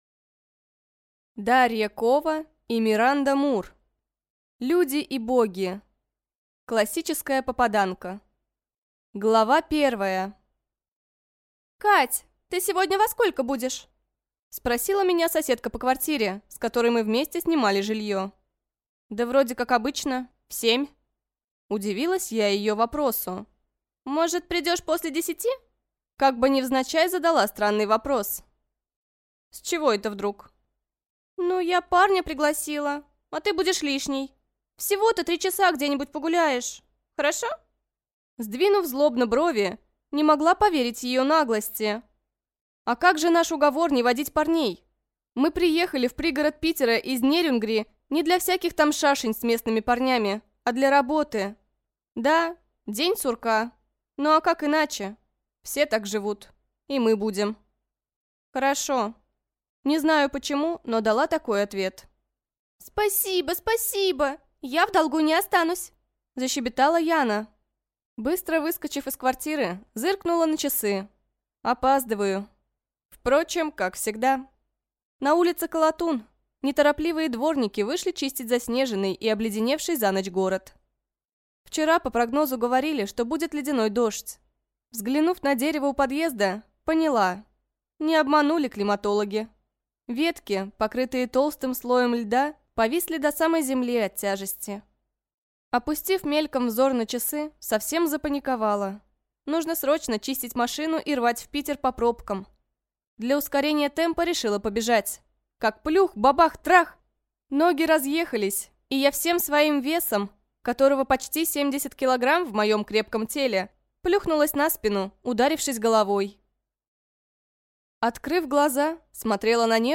Аудиокнига Люди и Боги | Библиотека аудиокниг
Прослушать и бесплатно скачать фрагмент аудиокниги